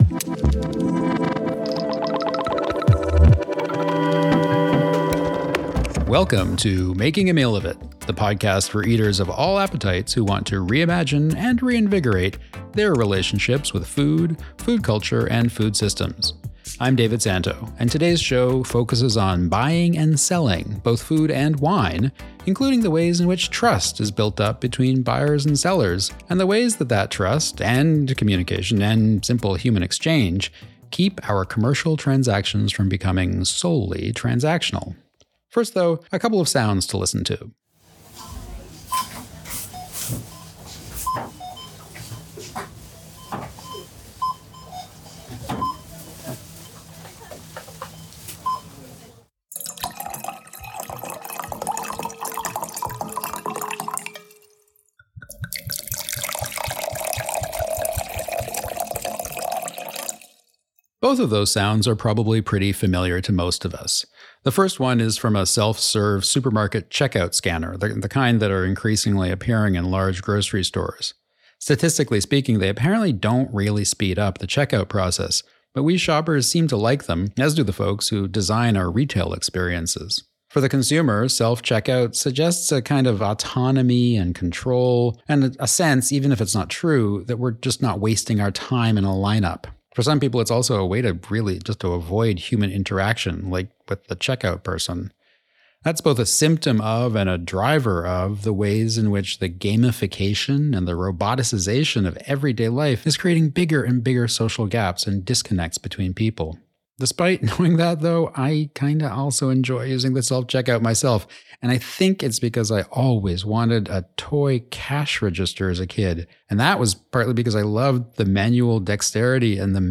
This episode focuses on the relationships that underlie the buying and selling of food (and wine), including the ways in which trust is built up through exchange and communication. We start off with some sounds of feedback— but not the awful screechy kind.